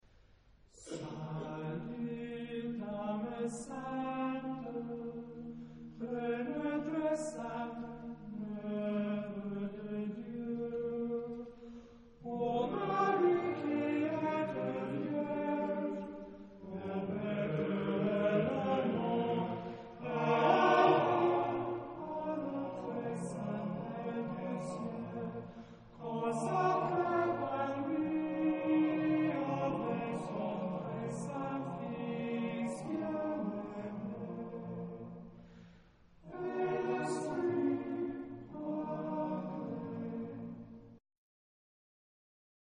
Genre-Style-Forme : Sacré
Caractère de la pièce : pieux ; élogieux
Type de choeur : TBarBarB  (4 voix égales d'hommes )
Tonalité : mi bémol majeur
Réf. discographique : Internationaler Kammerchor Wettbewerb Marktoberdorf